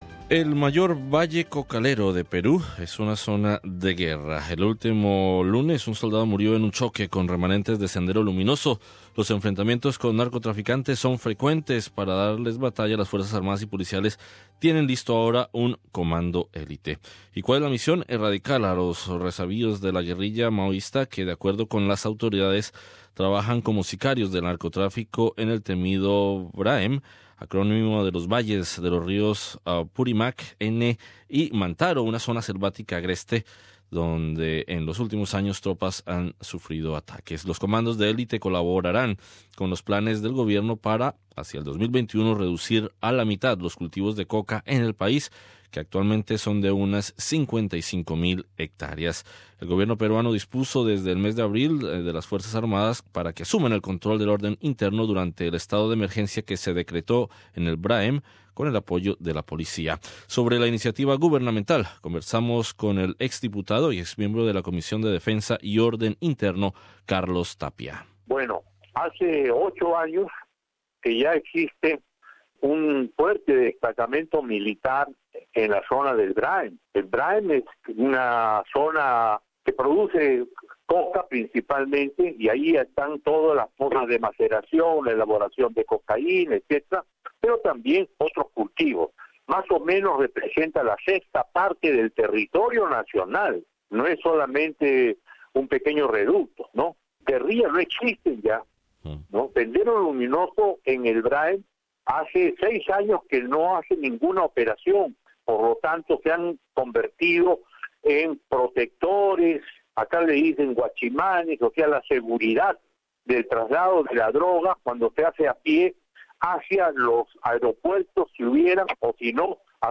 Entrevista con el ex Diputado y ex Miembro de la Comisión de Defensa y Orden Interno, Carlos Tapia.